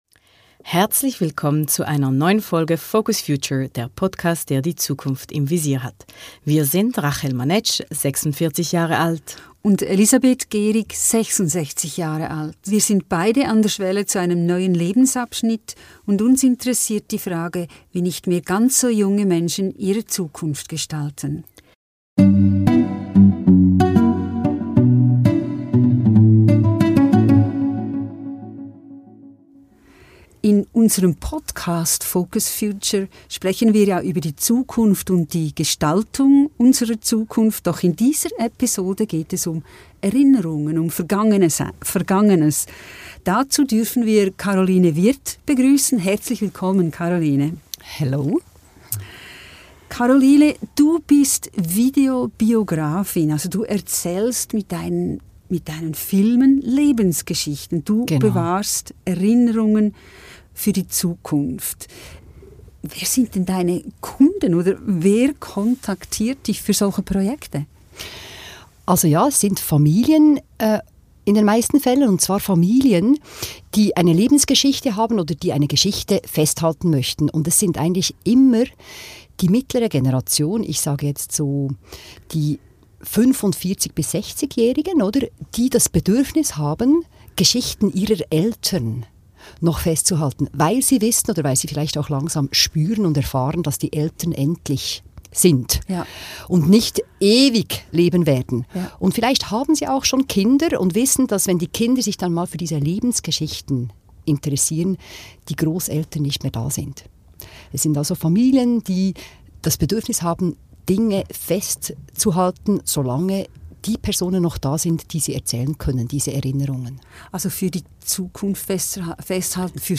Lebensgeschichten festhalten ~ FocusFuture - Zwei Generationen sprechen über die Zukunft.
Hört rein in dieses Gespräch über Familiengeschichten, Herkunft, Vergangenheit und Zukunft!